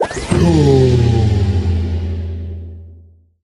Deploy SFX
部署音效
CR_monk_deploy_sfx_01.mp3